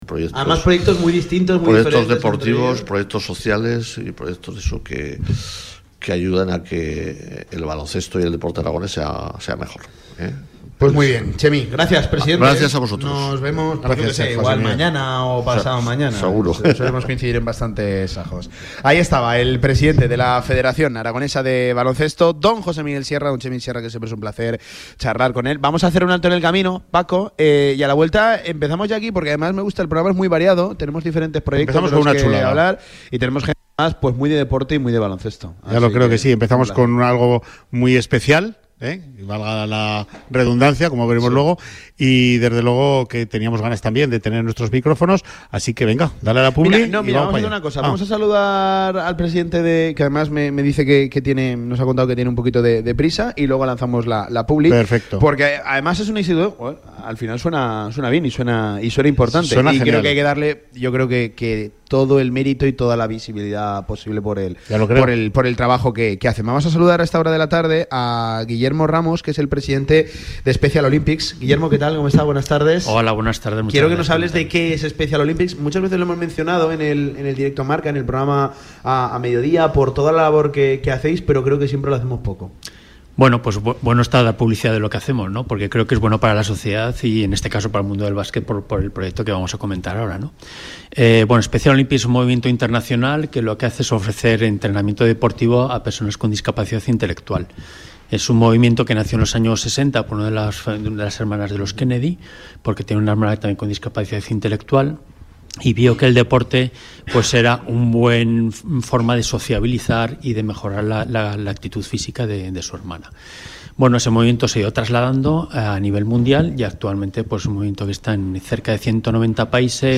Entrevista en Radio Marca sobre el baloncesto unificado
Os dejamos la entrevista que realizamos en la Federación de Baloncesto de Aragón hablando del proyecto de baloncesto unificado de SPECIAL OLYMPICS ARAGÓN